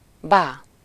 Ääntäminen
IPA : /ˈʌŋ.kəl/ IPA : /ˈʌŋk.l̩/